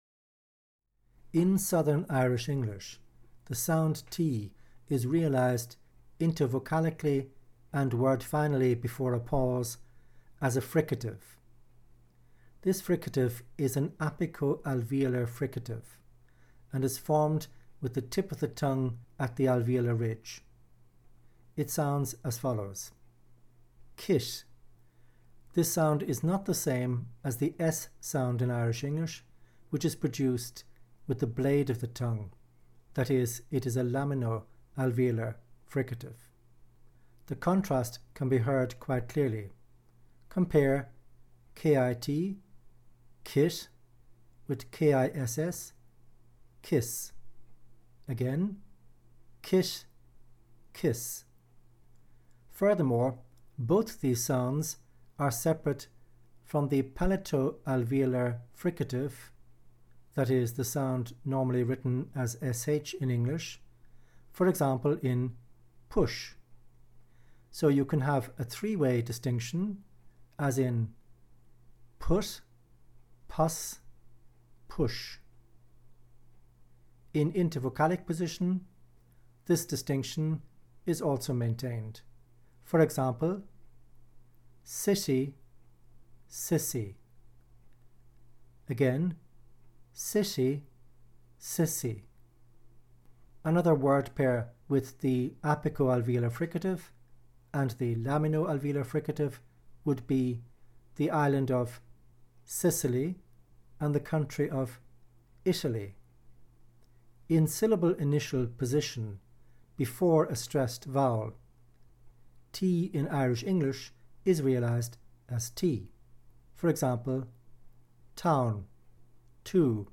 The following sound file discusses the realisation of lenited (fricative) t and contrasts it with both /s/ and /ʃ/.
T-lenited_S_SH_distinctions.mp3